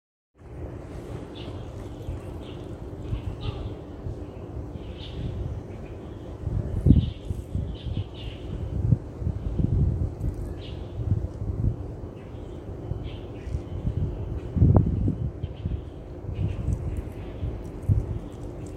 靜宜校園聲景地圖